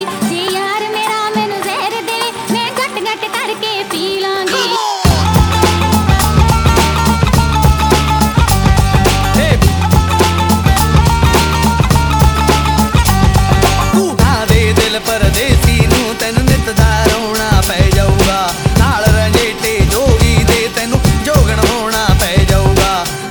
мощные
быстрые
индийские
alternative hip hop